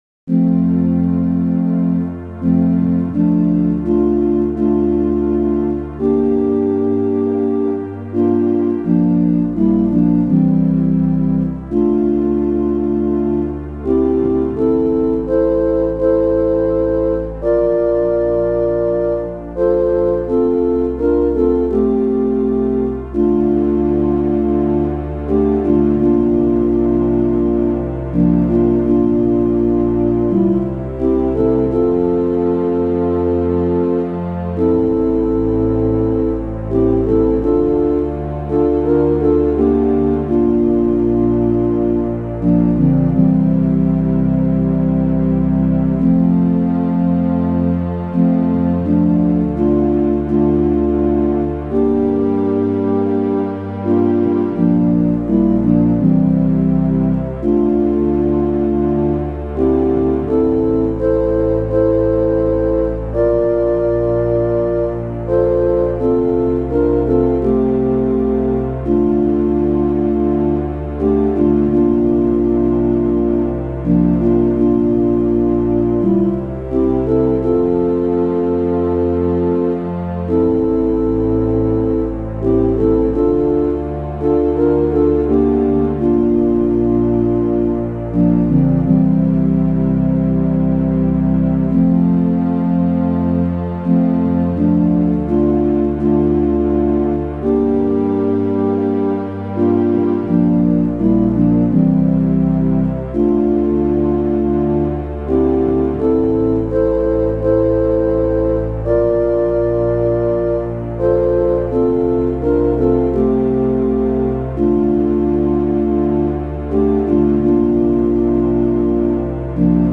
chant